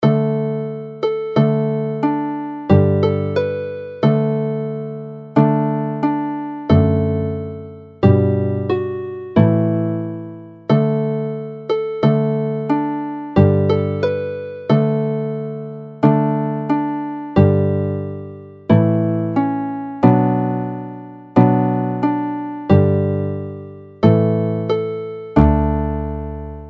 mp3 + cordiau